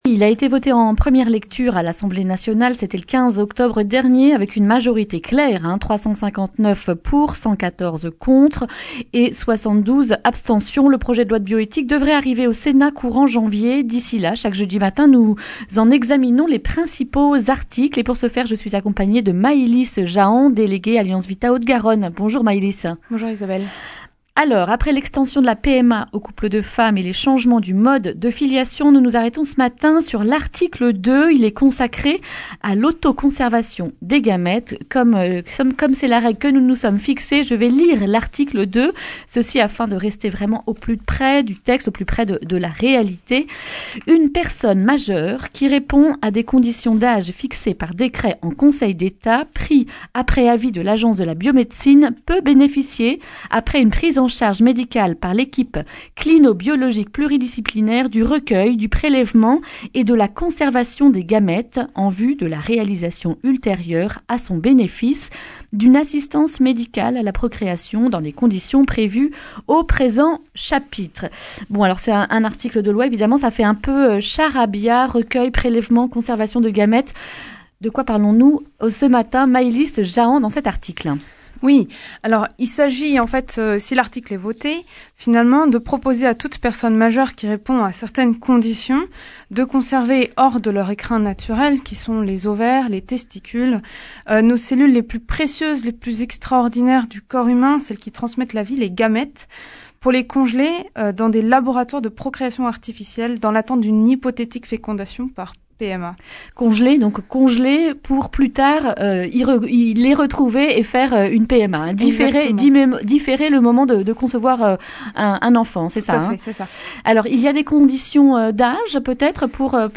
jeudi 21 novembre 2019 Le grand entretien Durée 11 min